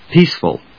peace・ful /píːsf(ə)l/
• / píːsf(ə)l(米国英語)